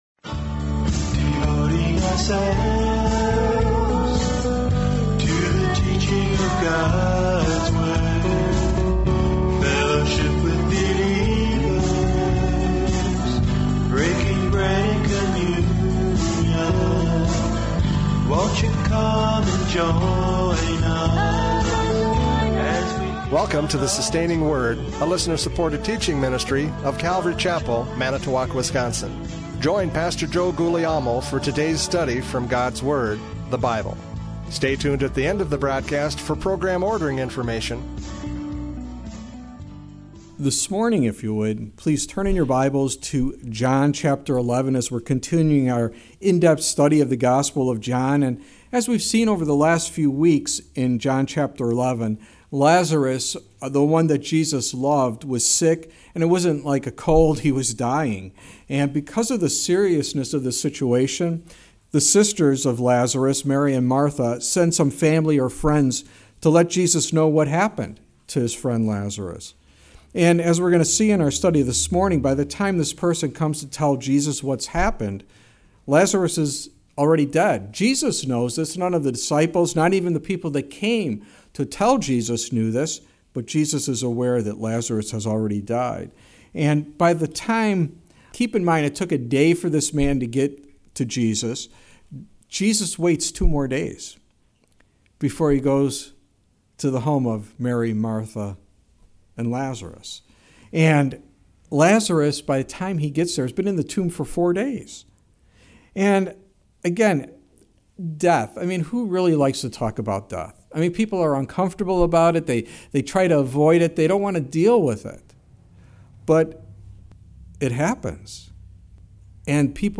John 11:17-27 Service Type: Radio Programs « John 11:4-16 Roadblocks to Serving!